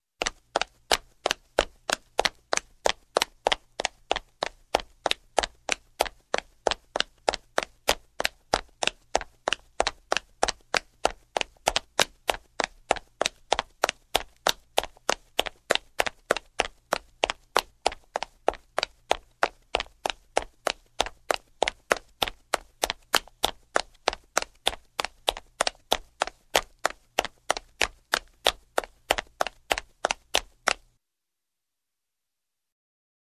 Звуки бегущего человека
Шаги женщины бегущей на каблуках